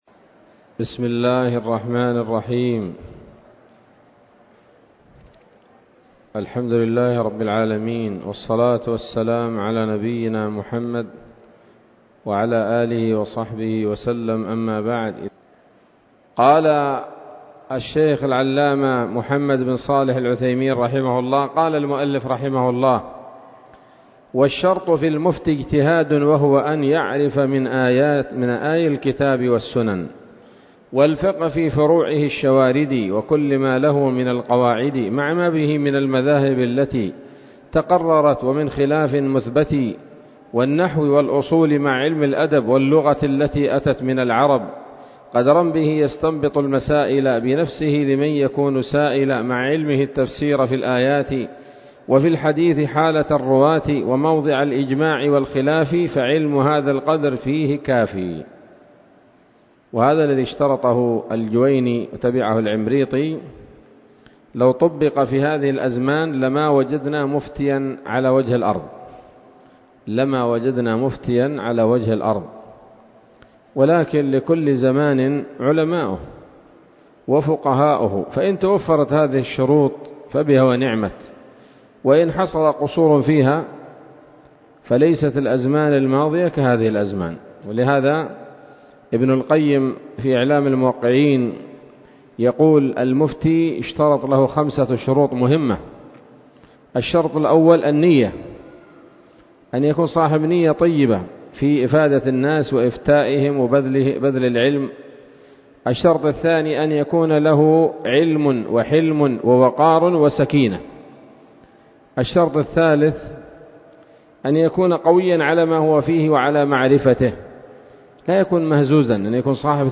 الدرس السابع والستون من شرح نظم الورقات للعلامة العثيمين رحمه الله تعالى